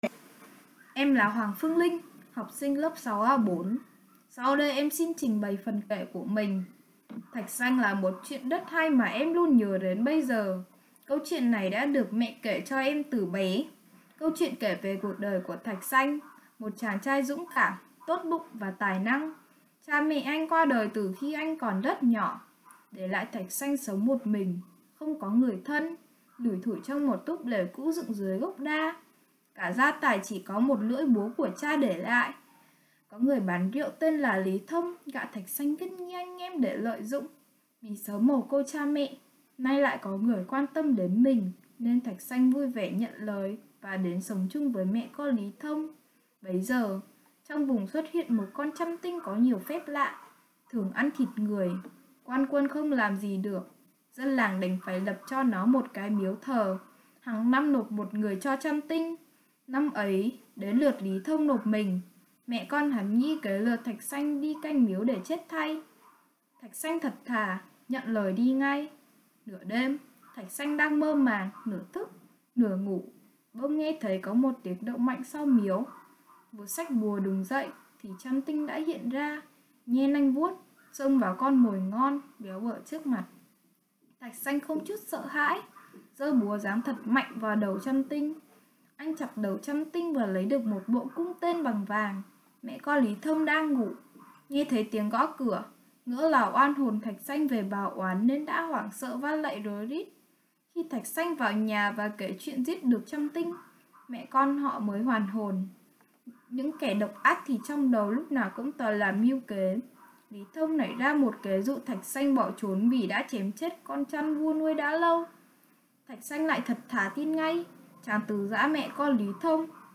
Sách nói | Truyện cổ tích: Thạch Sanh